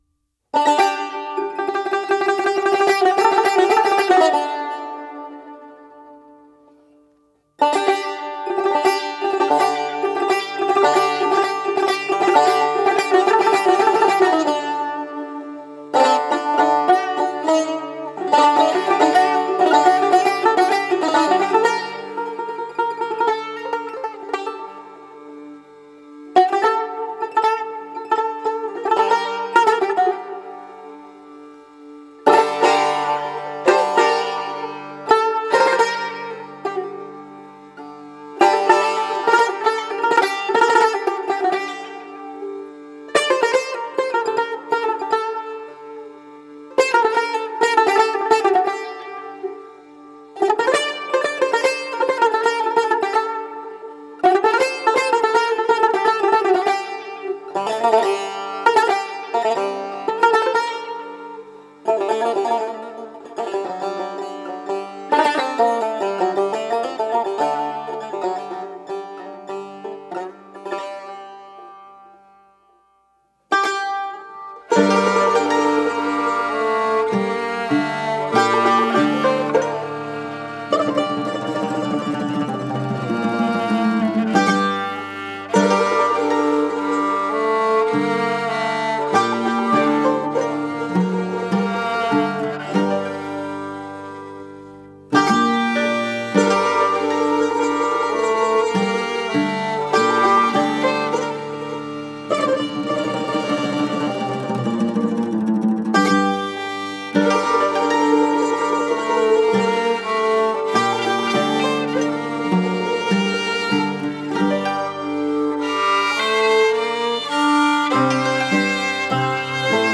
1. 1 Avaz
Bayat Esfahan